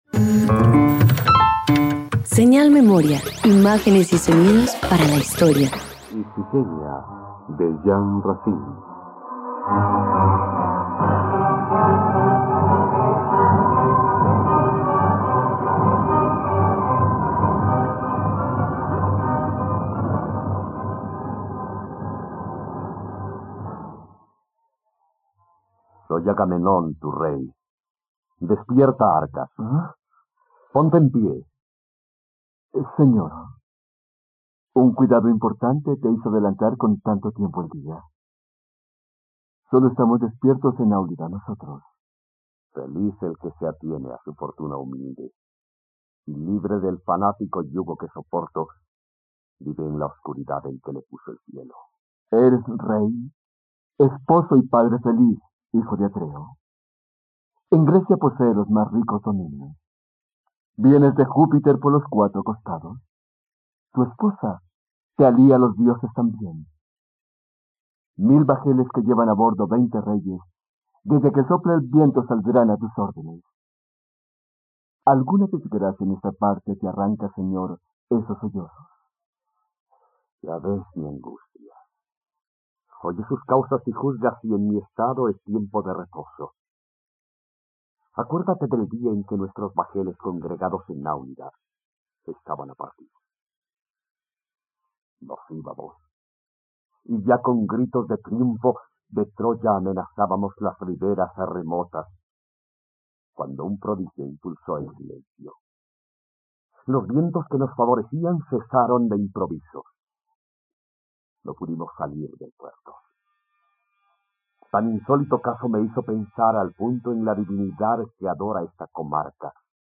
..Radioteatro. Escucha ahora la adaptación radiofónica de “Ifigenia", obra del dramaturgo francés Jean Racine, en la plataforma de streaming RTVCPlay.